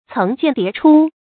层见叠出 céng jiàn dié chū
层见叠出发音